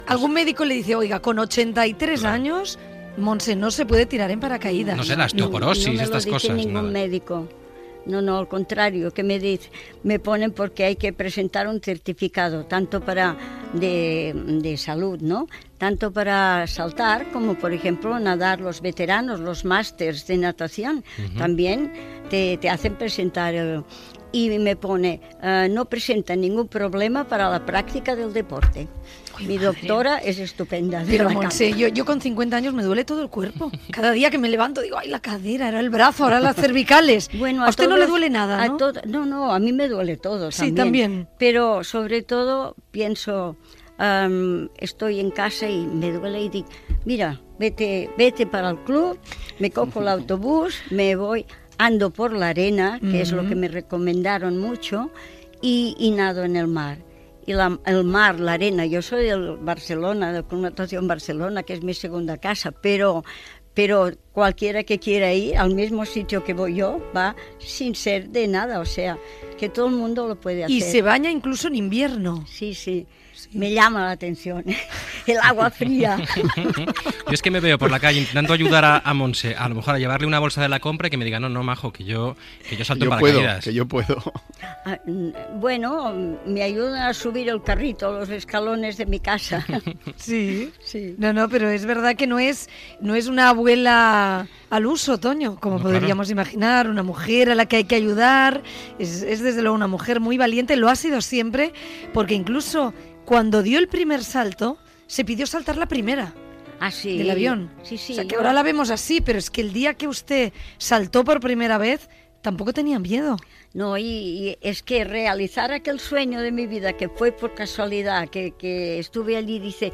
Info-entreteniment
Presentador/a
Nierga, Gemma